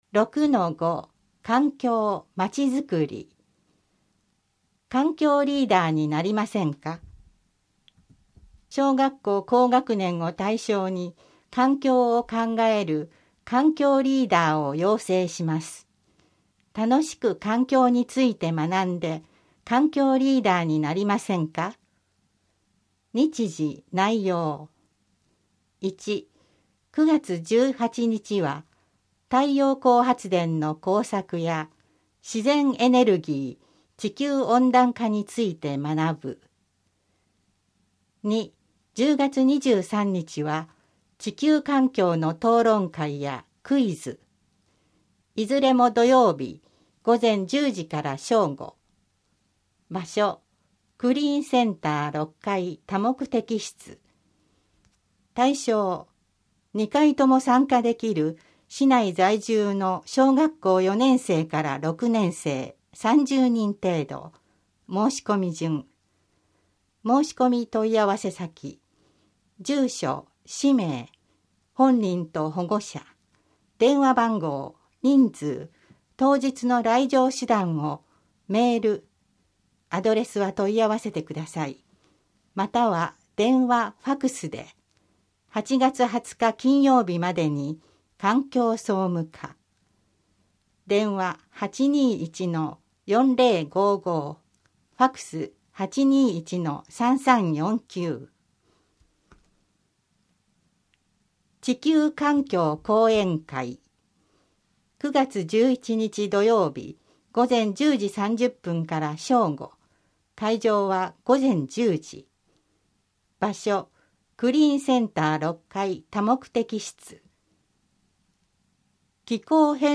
広報ねやがわ8月号の声の広報（音声版）を公開しています。